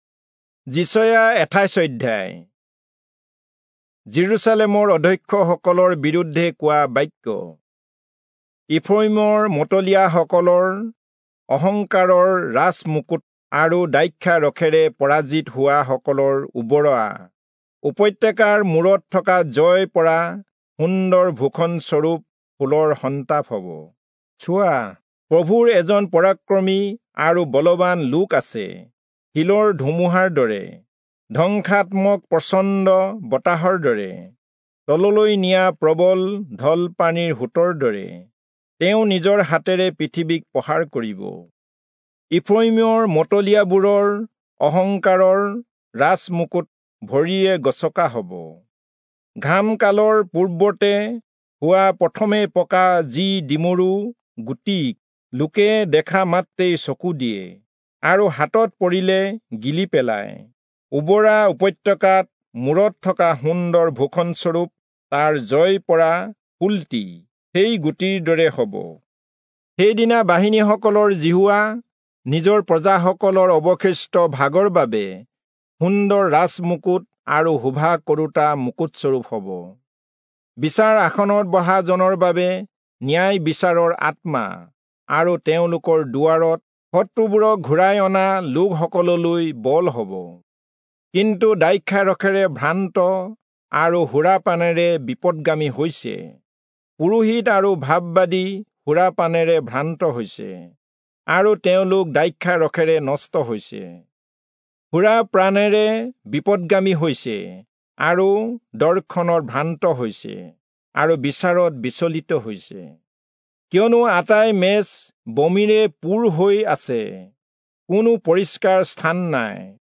Assamese Audio Bible - Isaiah 4 in Guv bible version